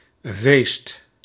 Ääntäminen
France: IPA: [pɛ]